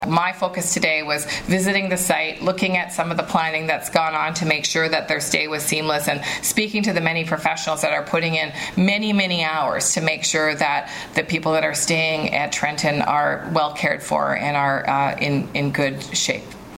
Patty Hajdu  told reporters at the Hastings Prince Edward Health Unit, things on the base are running like a well oiled machine.